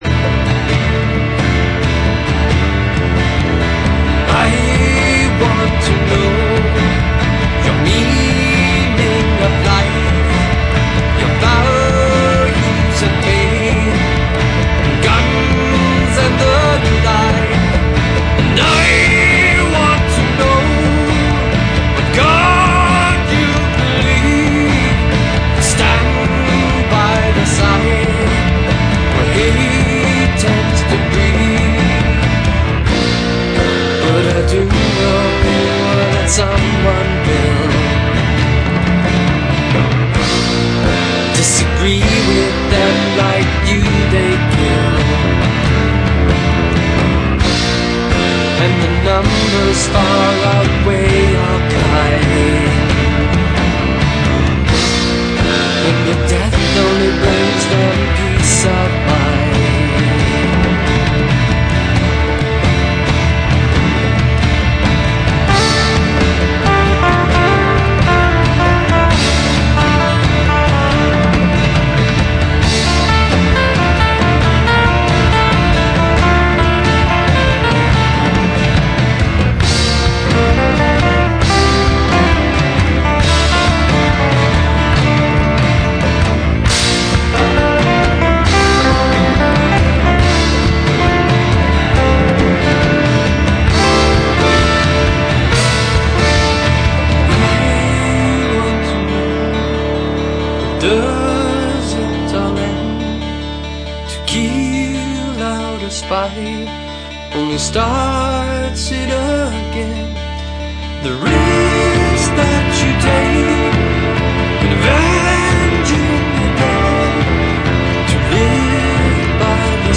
Modern Rock and Pop